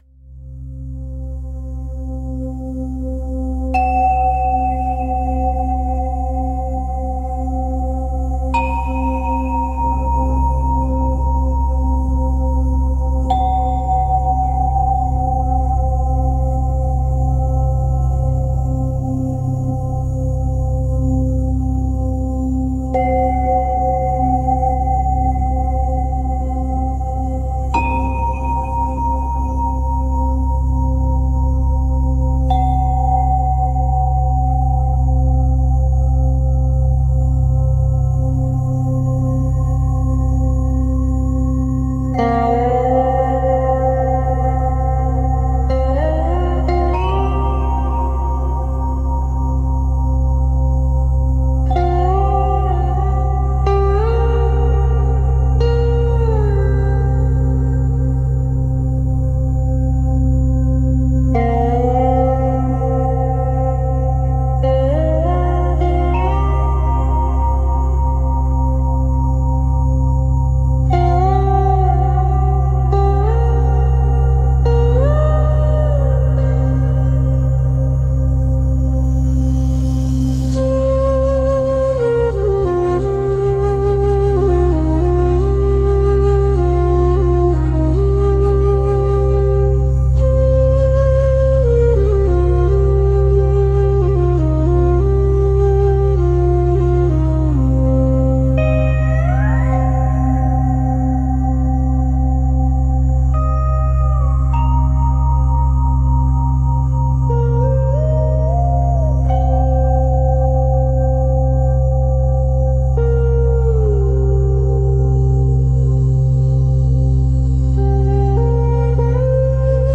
【助眠音乐】432Hz安全降落 - AI音乐
432赫兹正弦睡眠环境音，超慢28拍/分钟节奏，基础音轨搭配失谐垫片，1.8赫兹双耳Δ频，缓慢渐变至静音状态，无打击乐元素，逐渐过渡至Δ睡眠模式，婴儿般自然入睡，无持续低频动态积累，无缝衔接的平坦渐进 如果无法播放，请点击此处在新窗口打开 ## 432Hz安全降落 **提示词：** 432 Hz sine sleep ambient, ultra slow 28 BPM, foundation with detuning pads, 1.8 Hz binaural delta, slow morph to silence, no percussion, gradually descending into delta sleep, fall asleep like a baby, no build-up constant low dynamics seamless flat progression **科学解释：** 调整频率稳定心率，结合δ节拍，心律变异性最佳